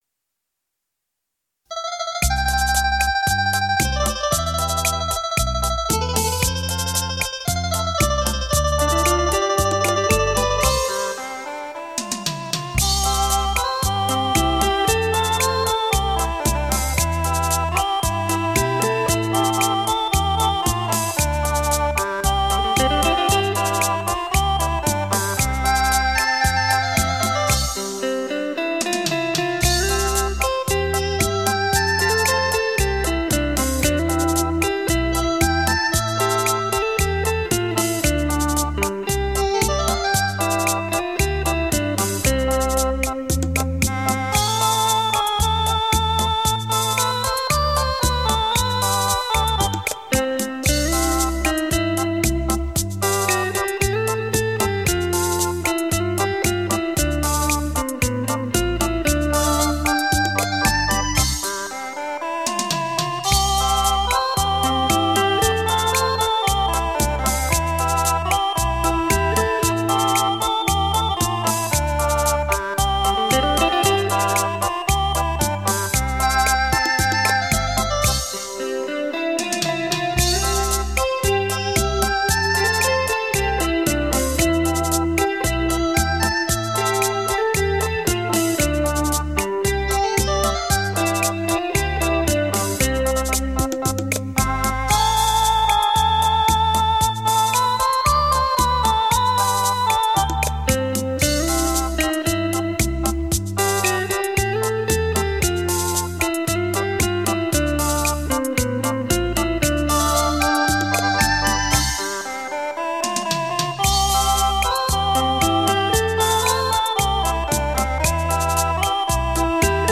轻快、跳耀、如首首小诗中吟诵，如条条小溪在流淌，如阵阵清风在抚慰，这如歌的行板呀！